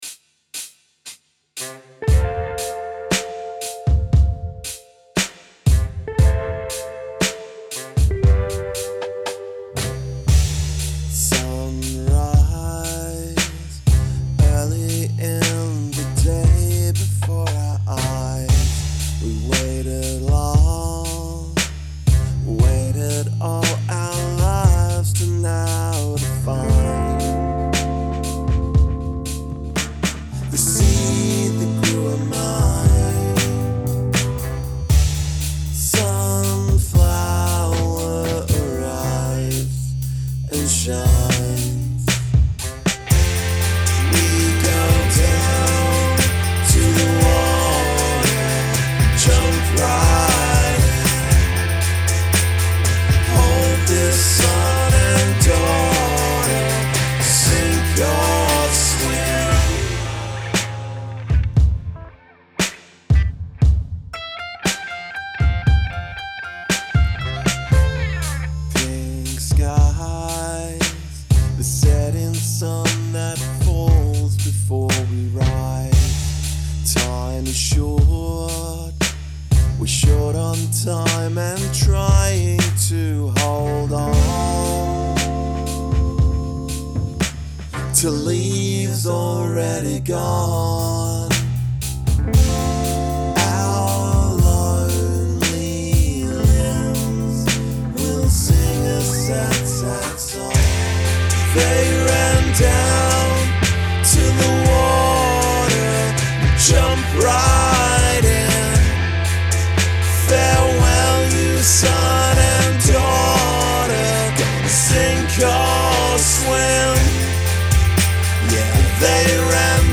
Slow.